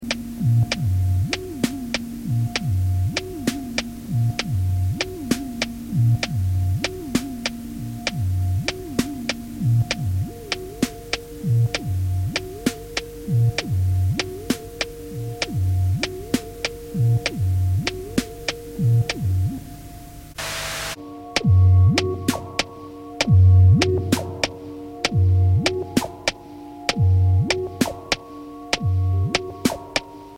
synth funk